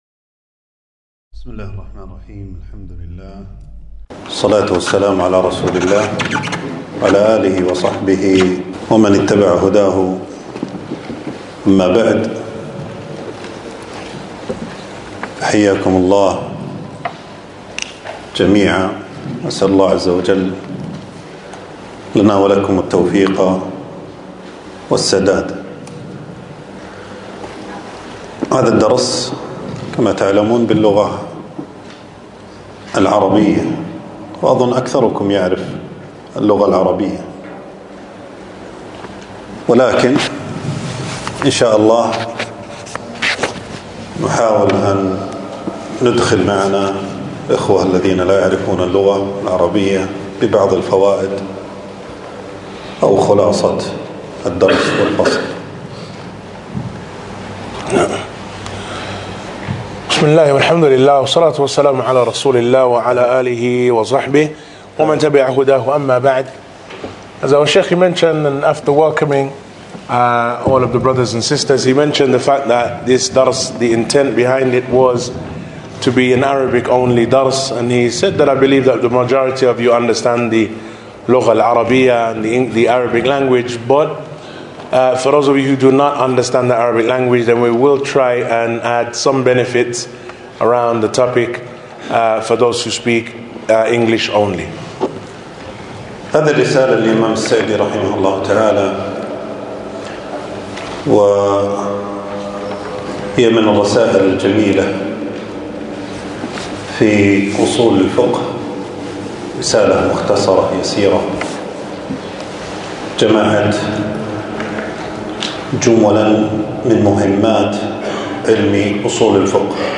تنزيل تنزيل التفريغ العنوان: شرح رسالة لطيفة جامعة في أصول الفقه المهمة للعلامة السعدي. (الدرس الأول) ألقاه
المكان: درس ألقاه يوم السبت 17 جمادى الأول 1447هـ في مسجد السعيدي.